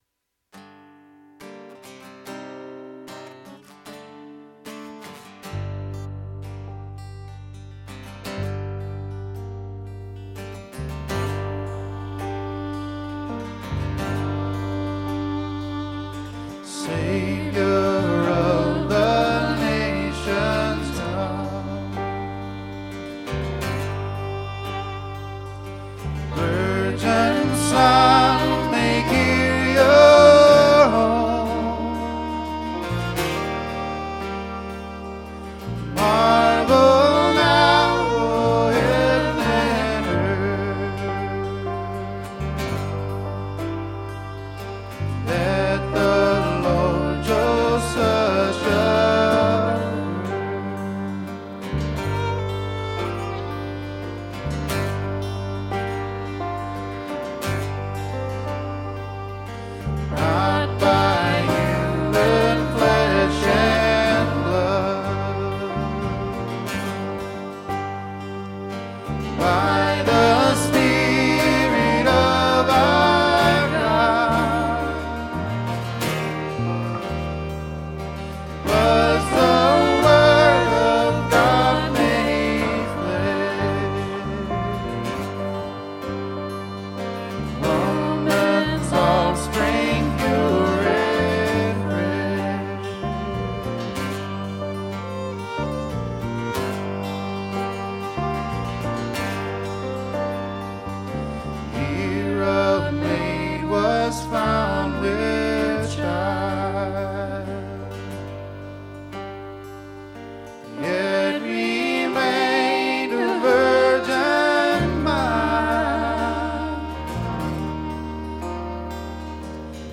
This is one of my favorite Advent hymns.
Performed by Christ Our King Musicians
Vocals
Violin
Piano
Guitar
Bass